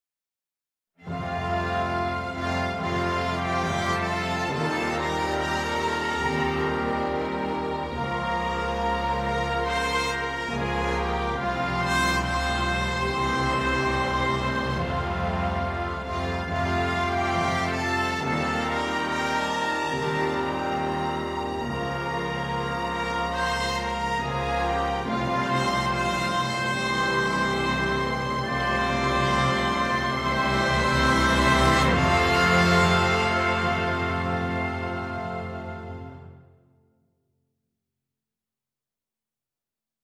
Môžete si ju vypočuť v orchestrálnom prevedení.